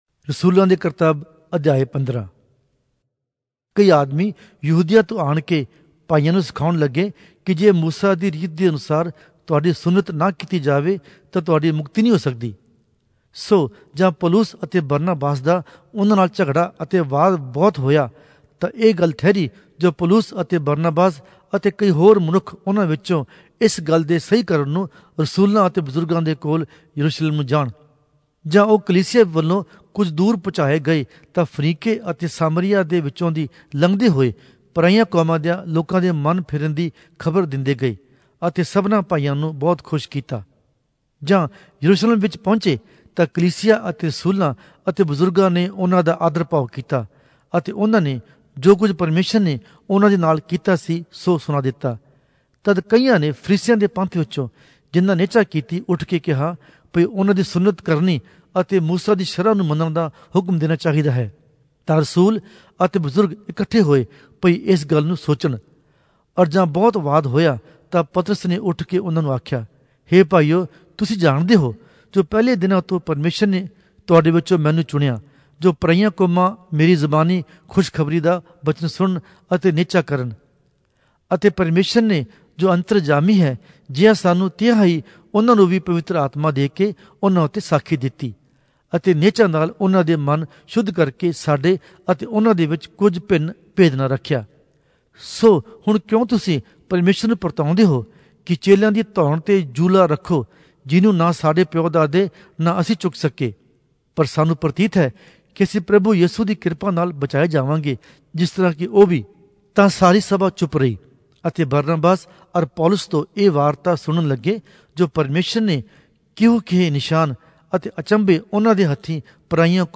Punjabi Audio Bible - Acts 9 in Gnttrp bible version